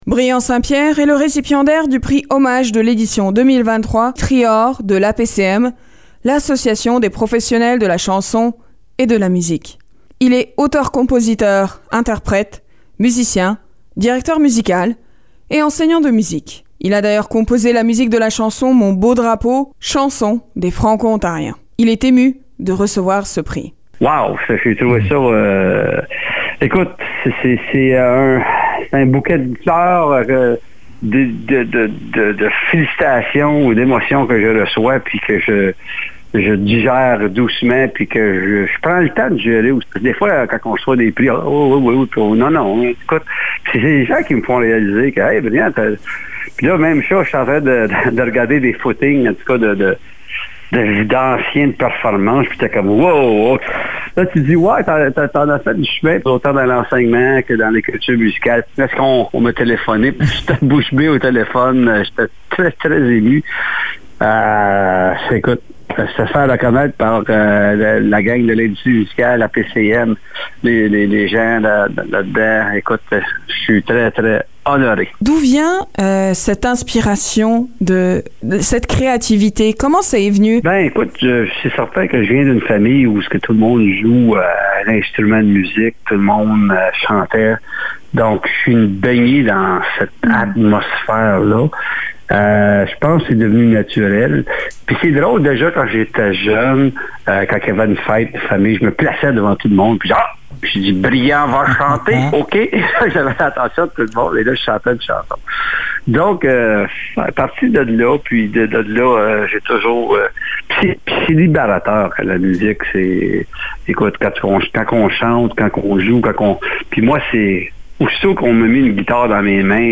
L’entrevue